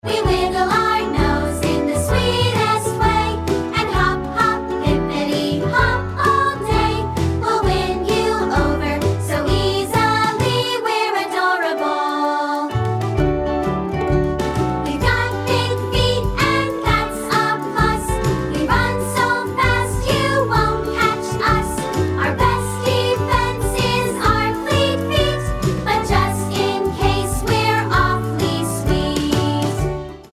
furry program for Kindergarten – Grade 3 singers.